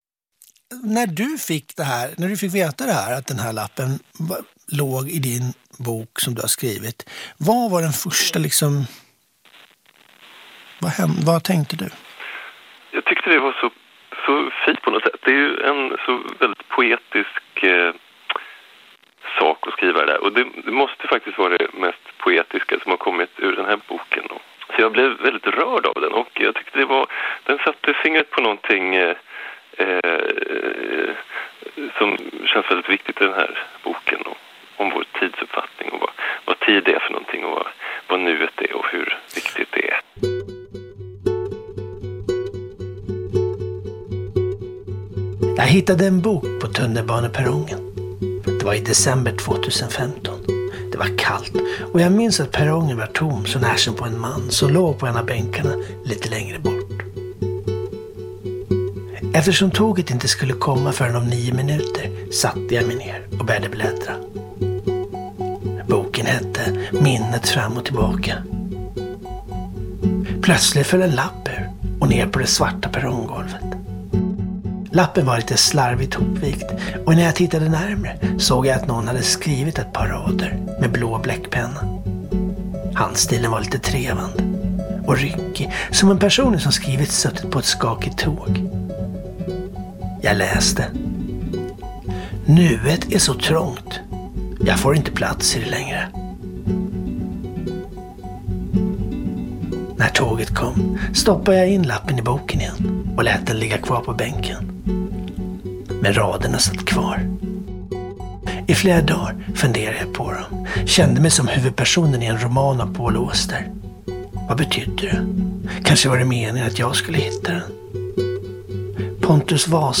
En kulturdokumentär om nuet, minnet och mycket mer.